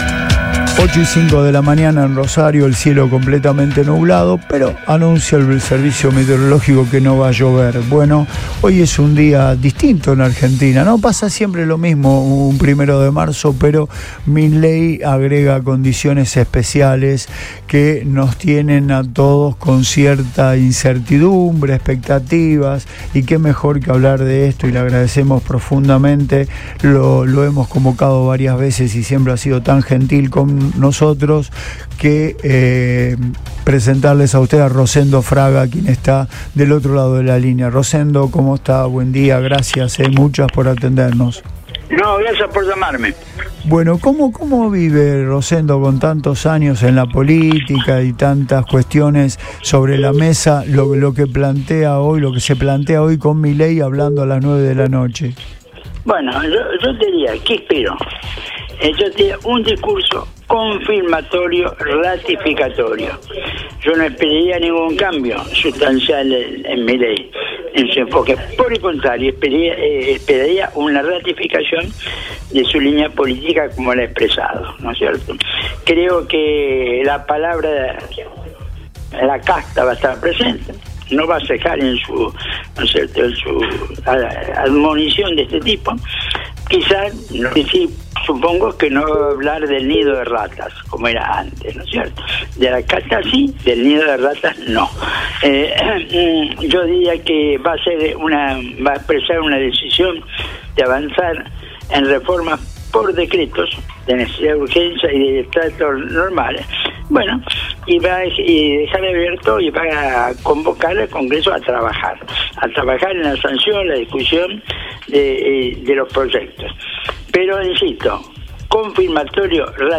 EN RADIO BOING
El analista político Rosendo Fraga dialogó con Radio Boing este viernes, en la previa de la apertura de sesiones del Congreso de la Nación.